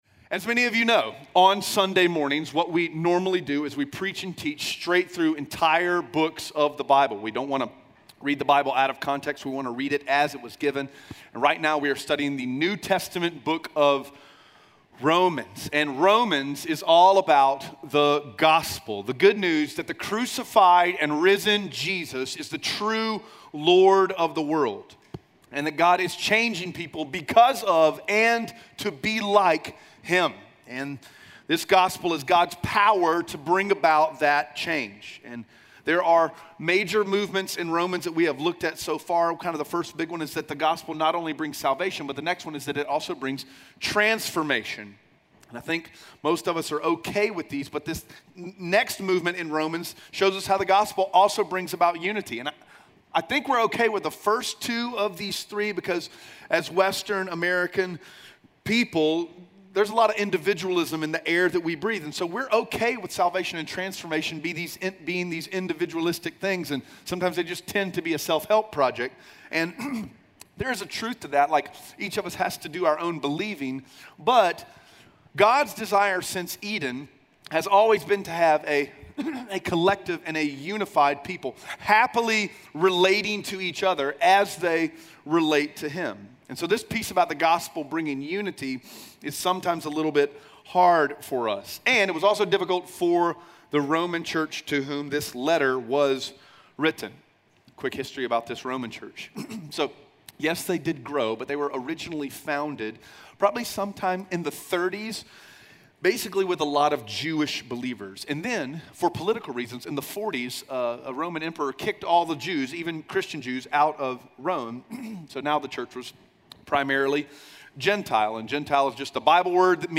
Romans 11:1-24 Audio Sermon Notes (PDF) Onscreen Notes Ask a Question The gospel is the good news that the crucified and risen Jesus is the true Lord of the world.